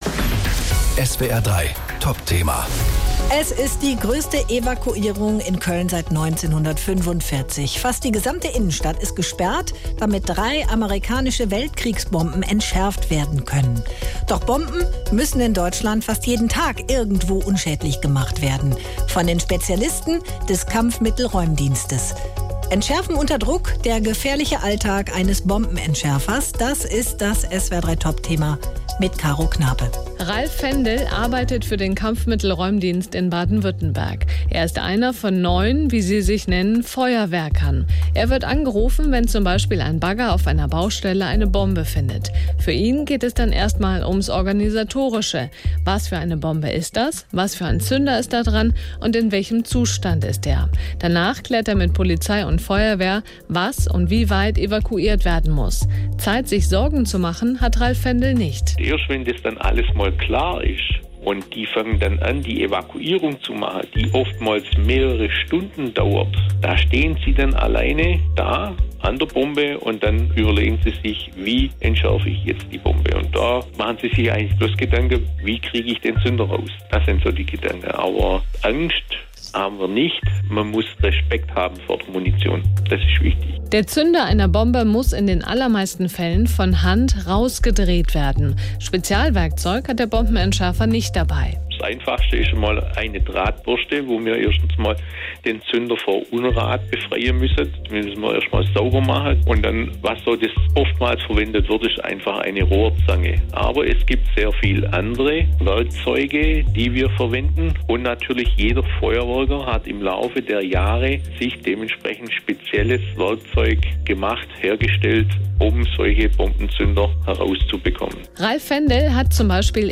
Der Grund: Im Stadtteil Deutz wurden drei Bomben aus dem Zweiten Weltkrieg gefunden. Viele Menschen müssen deshalb ihre Wohnungen verlassen, damit sie entschärft werden können. Wir haben mit einem Experten vom Kampfmittelräumdienst gesprochen.